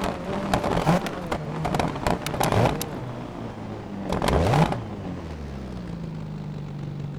Index of /server/sound/vehicles/lwcars/delta
slowing.wav